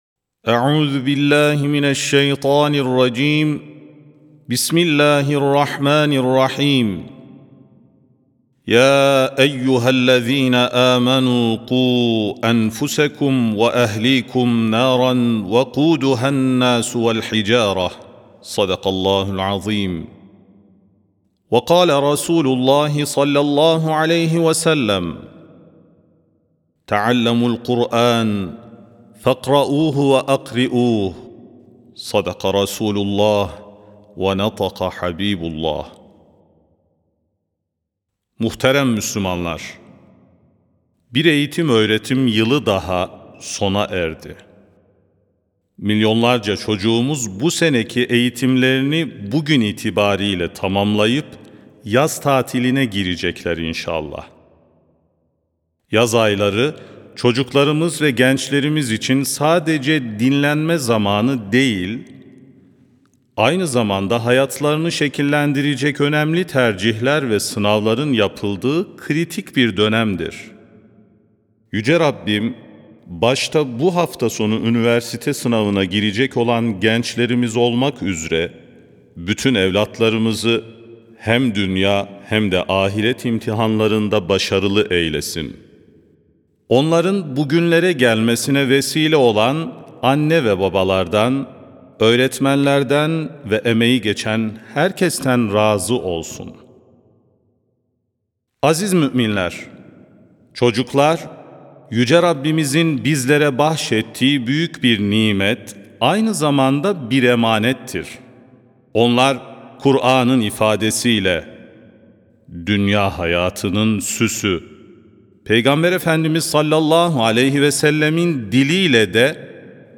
Sesli Hutbe (Her Yaz Yeni Bir Heyecan, Cami, Çocuk ve Kur’an).mp3